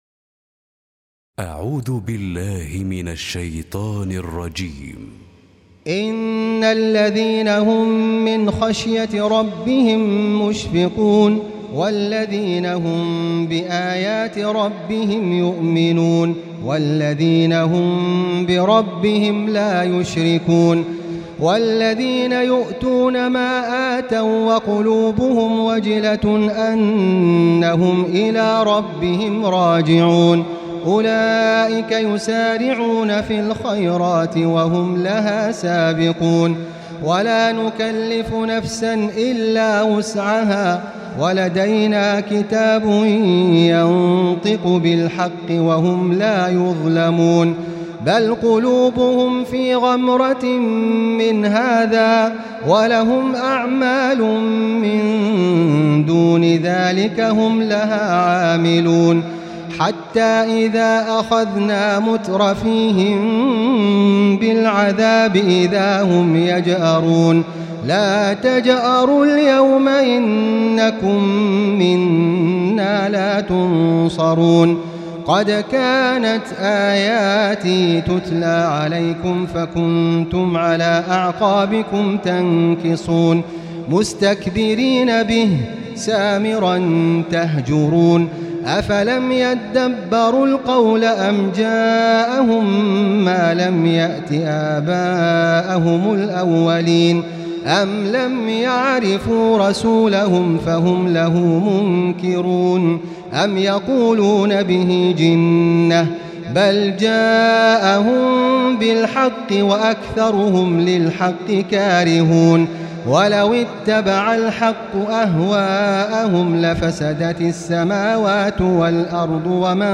Recitime